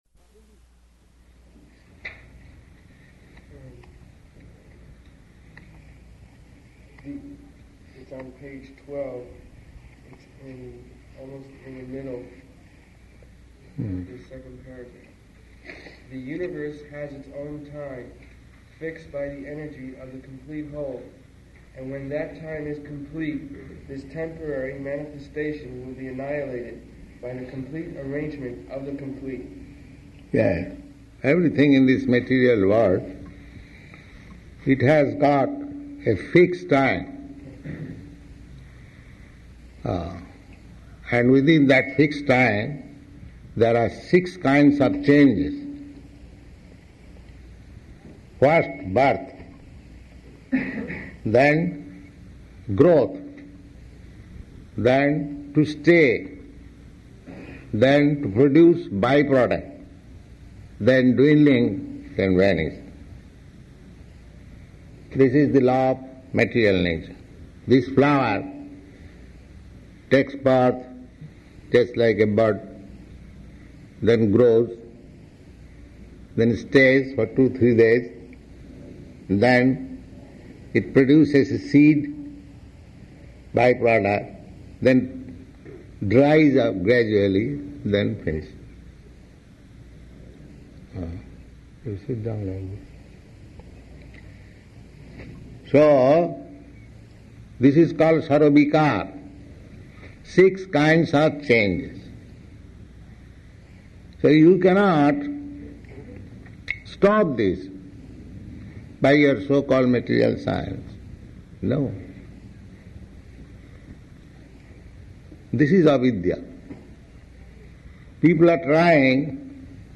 April 27th 1970 Location: Los Angeles Audio file